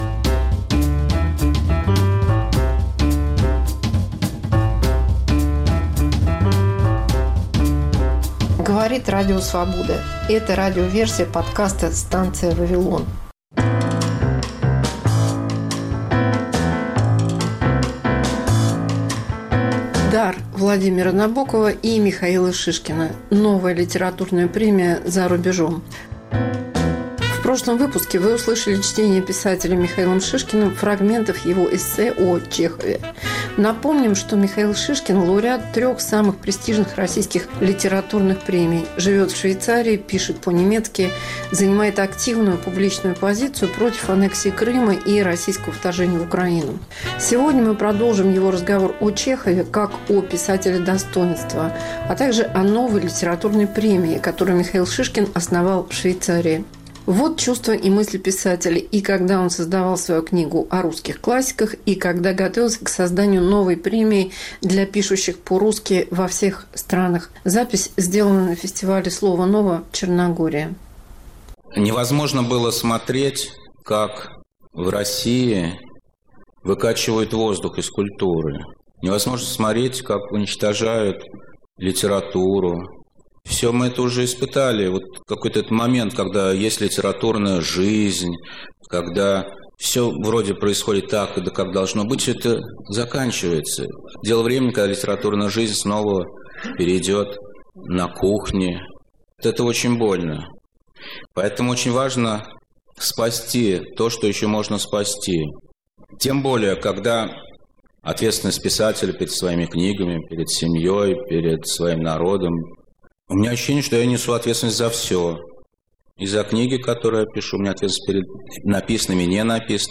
Мегаполис Москва как Радио Вавилон: современный звук, неожиданные сюжеты, разные голоса